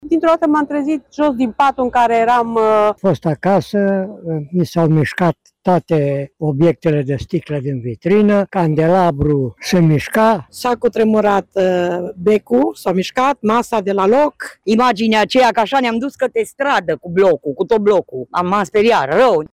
„Dintr-o dată m-am trezit jos, din patul în care eram”, spune o arădeancă.
„Eram acasă. Se mișcau toate obiectele de sticlă din vitrină, candelabrul se mișca”, își amintește un arădean.
„S-a cutremurat becul, masa s-a mișcat”, spune o altă arădeancă.